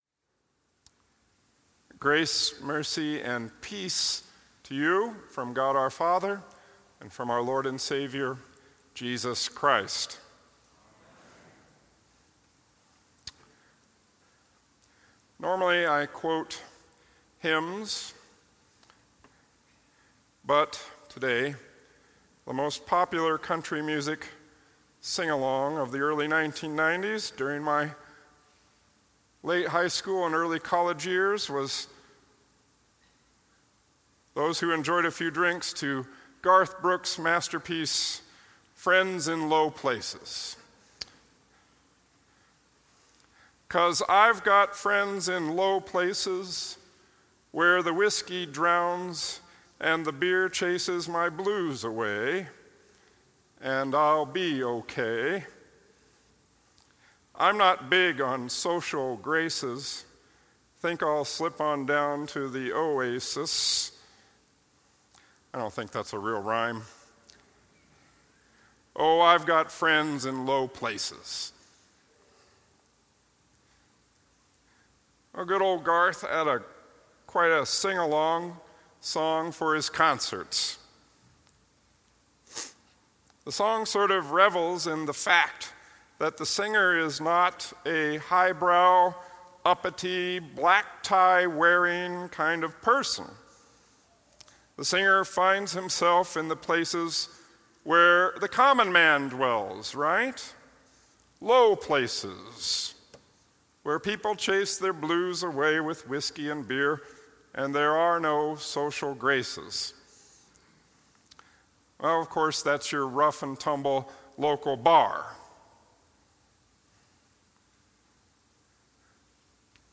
– Ninth Sunday after Trinity (2018) – Immanuel Evangelical Lutheran Church